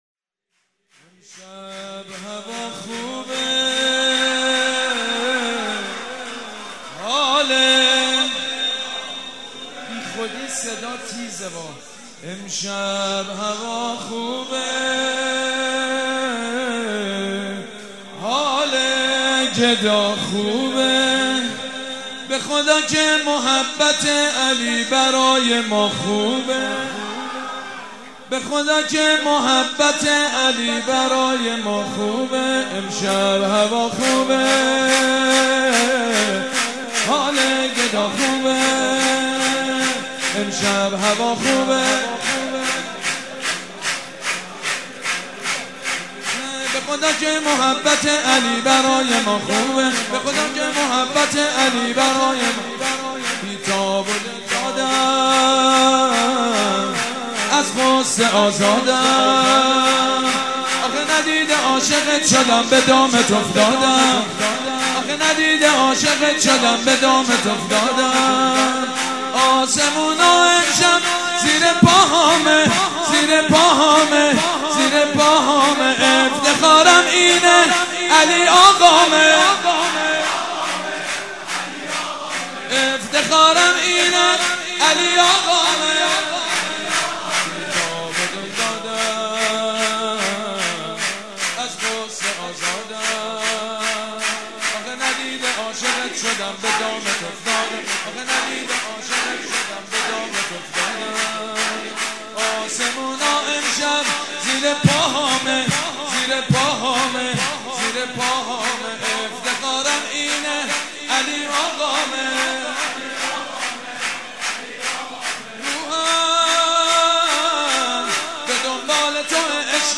شور
مداح
حاج سید مجید بنی فاطمه
ولادت حضرت محمد (ص) و امام صادق (ع)